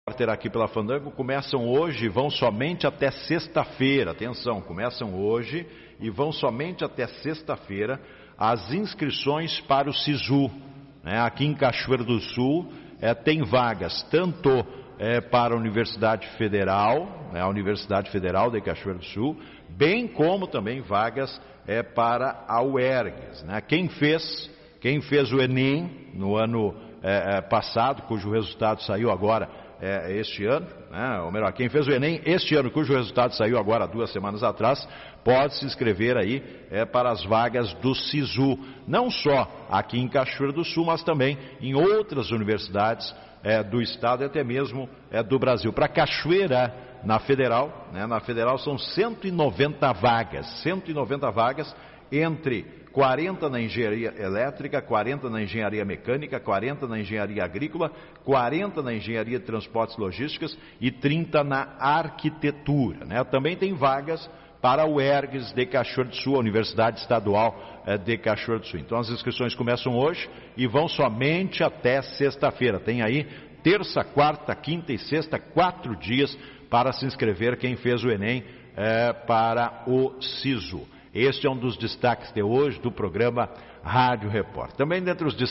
Matérias veiculadas em rádios no mês de abril de 2021.